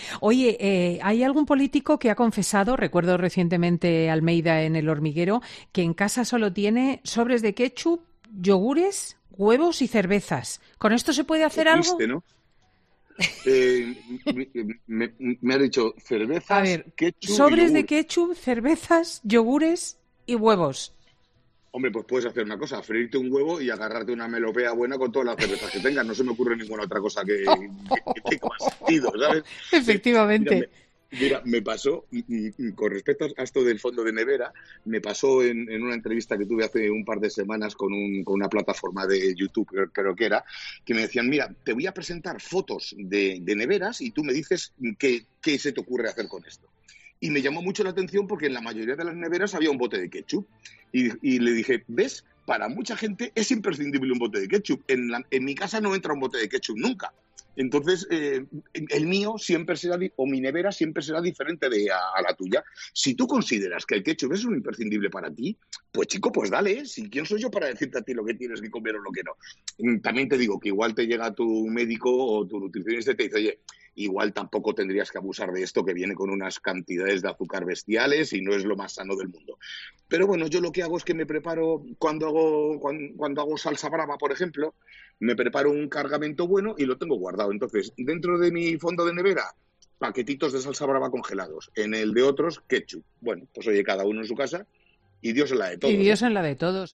En un momento de la entrevista Cristina le lanzaba una pregunta muy directa a Chicote sobre el alcalde de Madrid y sus hábitos culinarios: “Hay algún político que ha confesado, recuerdo a Almeida recientemente en 'El Hormiguero', que en casa sólo tiene sobres de ketchup, yogures, huevos y cerveza. ¿Con esto se puede hacer algo?”, proponía la presentadora de Fin de Semana.